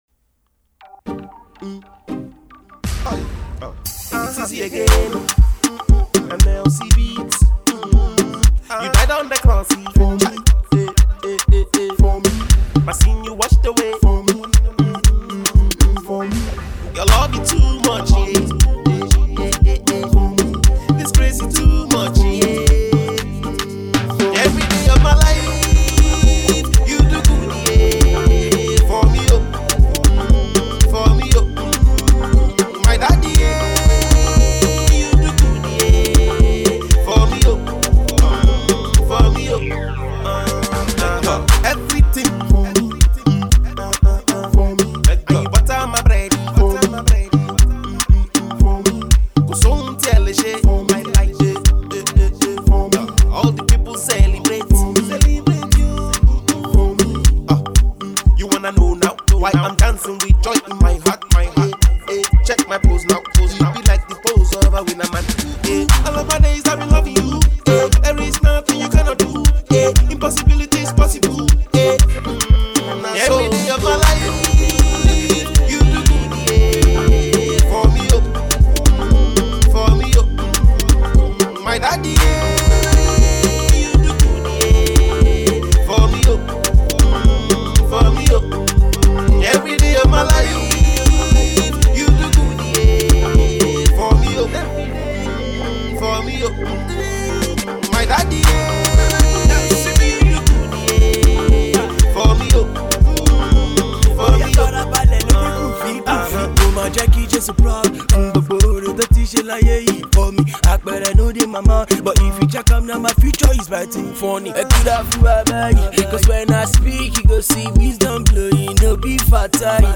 known for dexterity in his delivery of Afro-gospel music